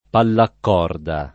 pallacorda [ pallak 0 rda o pallakk 0 rda ]